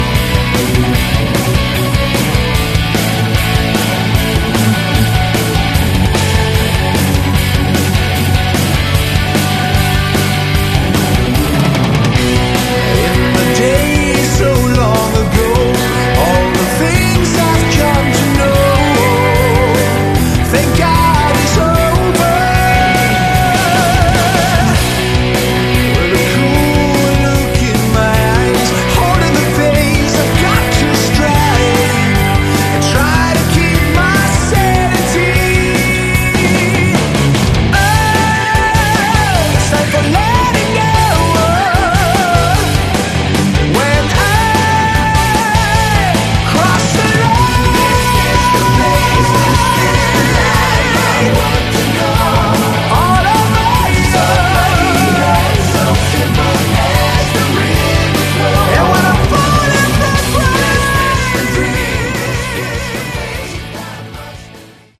Category: Melodic Hard Rock / Metal